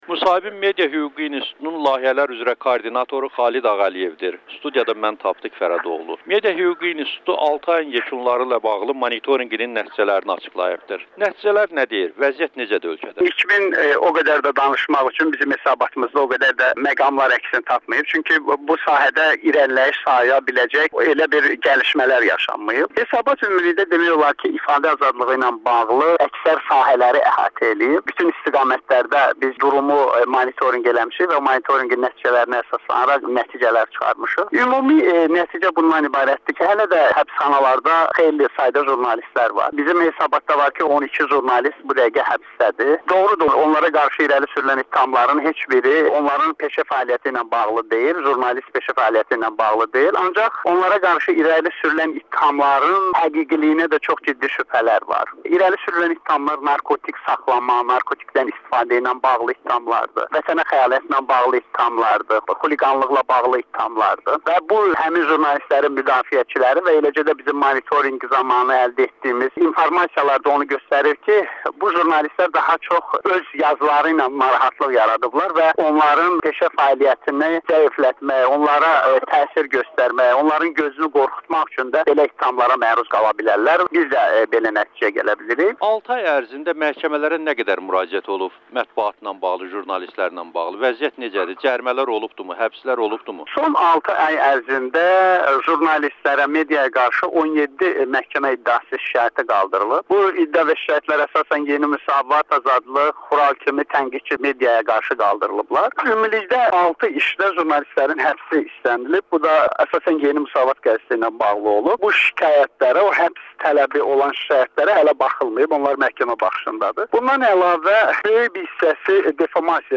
müsahibə